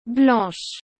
Já blanche tem um som mais aberto, parecido com blãsh.